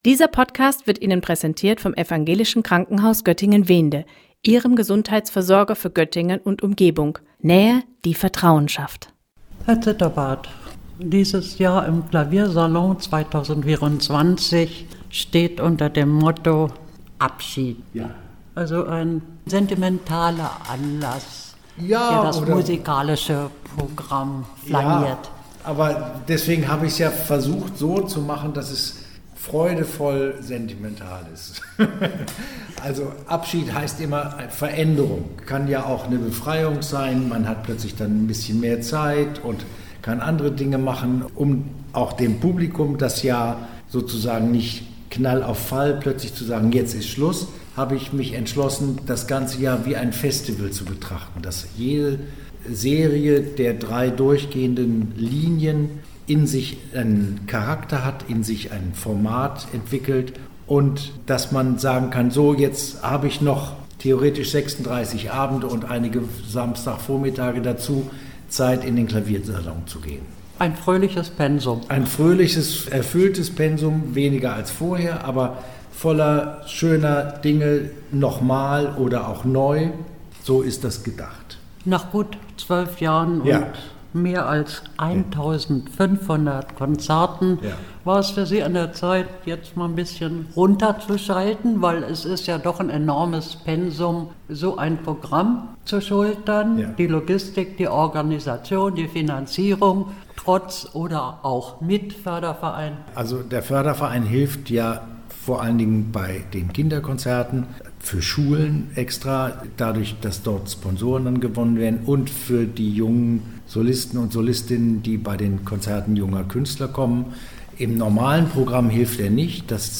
Die musikalische Abschiedssaison im Göttinger Clavier-Salon – Gespräch